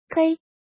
怎么读
kēi
kei1.mp3